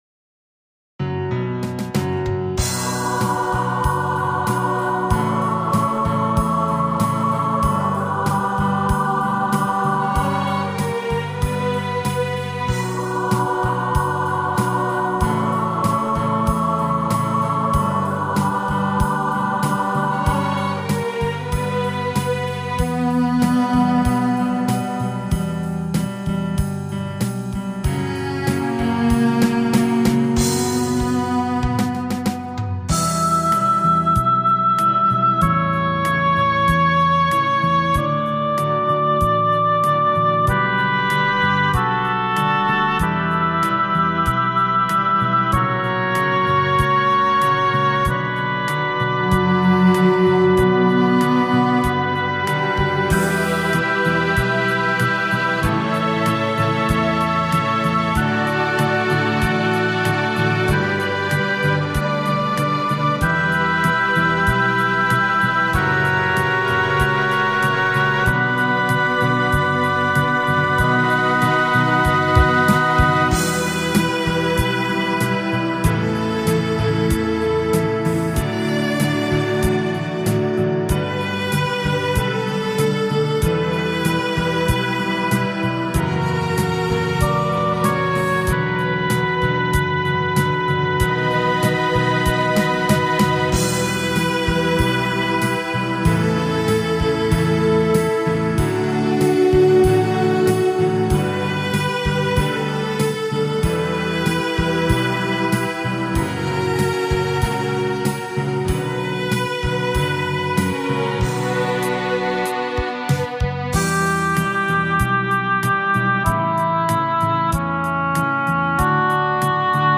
инструментал